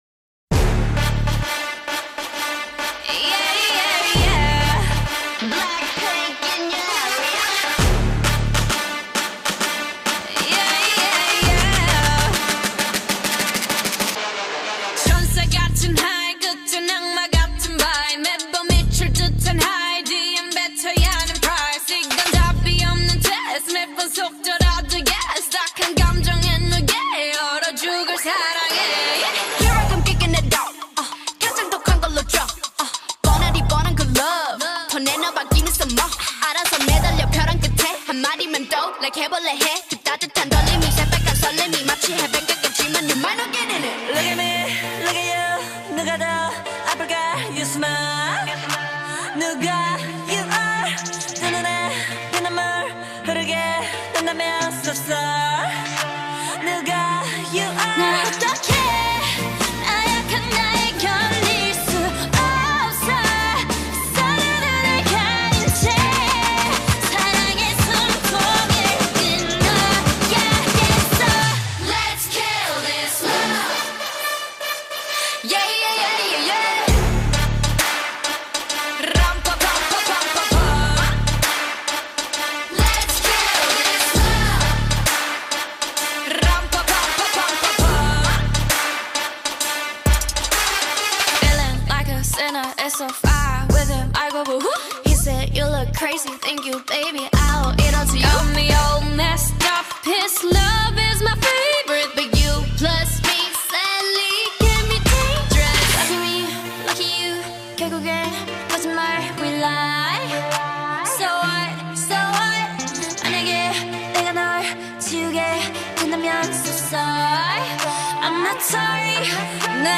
South Korean girl group